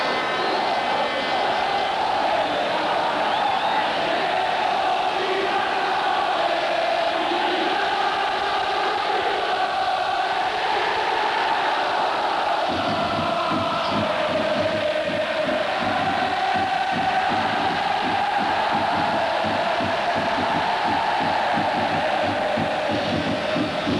Semifinale Coppa dei Campioni